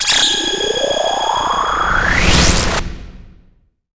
SFX激光波出现的特效音效下载
SFX音效